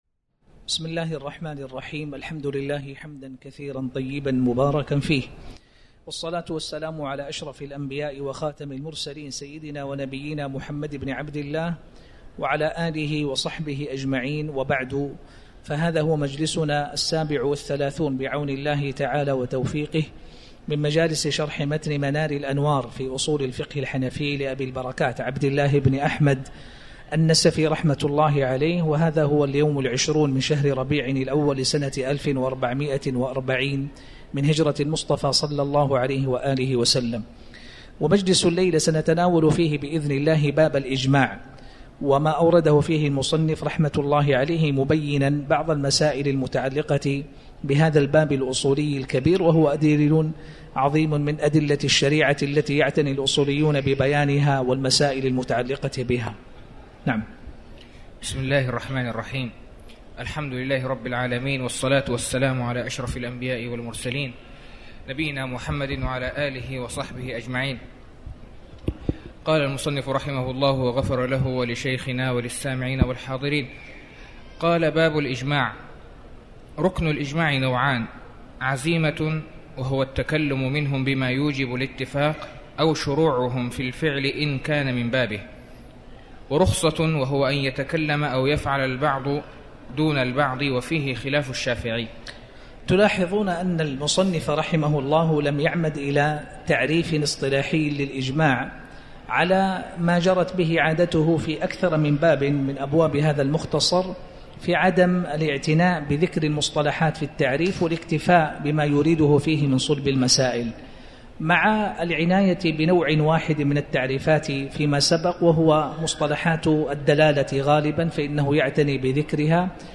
تاريخ النشر ٢٠ ربيع الأول ١٤٤٠ هـ المكان: المسجد الحرام الشيخ